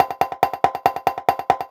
K-1 Perc 3.wav